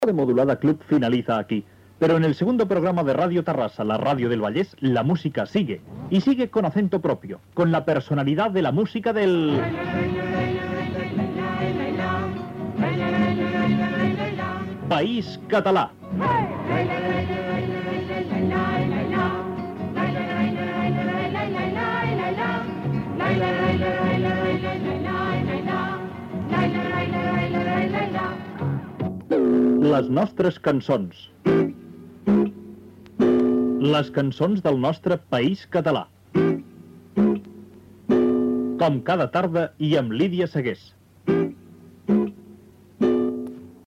Careta del programa
FM